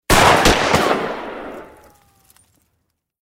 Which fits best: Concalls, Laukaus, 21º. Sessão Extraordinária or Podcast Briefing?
Laukaus